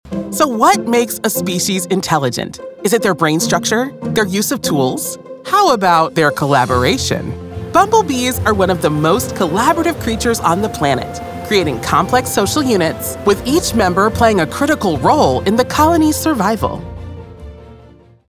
Never any Artificial Voices used, unlike other sites.
Yng Adult (18-29) | Adult (30-50)